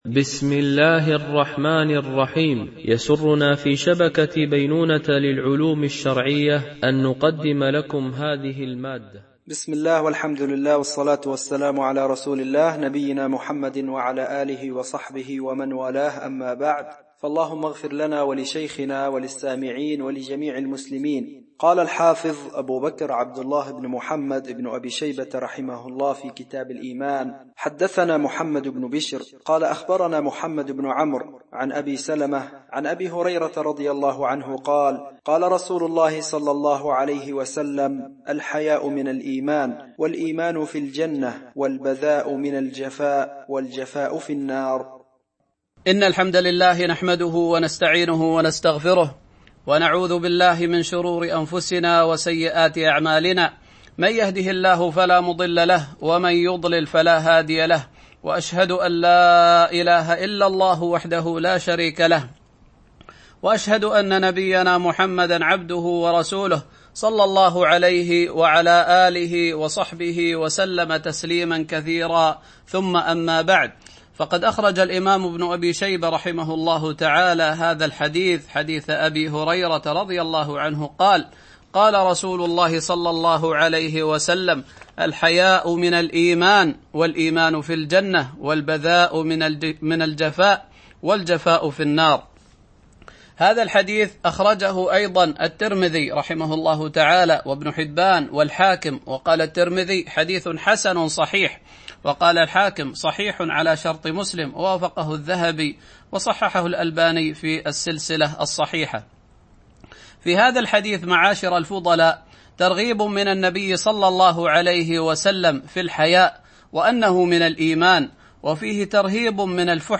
شرح كتاب الإيمان لابن أبي شيبة ـ الدرس 13 ( الحديث 42 )